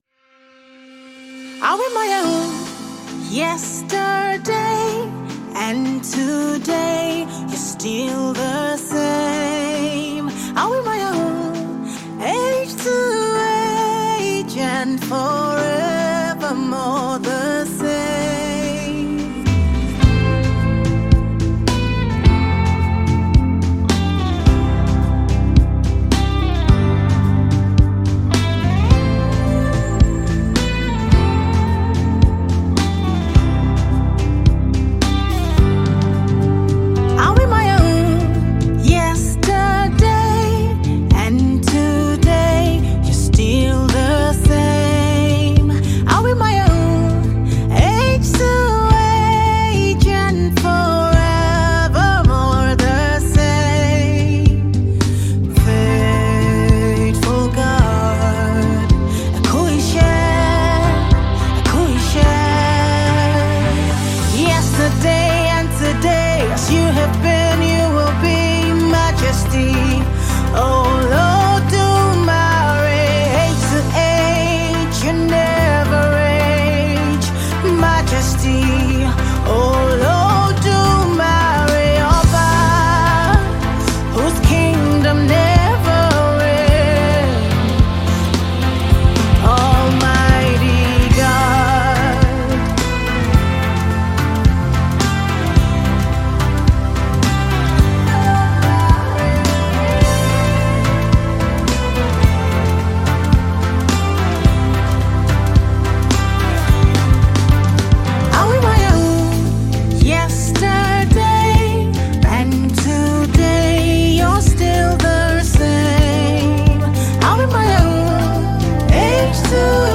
Mp3 Gospel Songs
a Female Nigerian gospel songstress
It was her dazzling studio album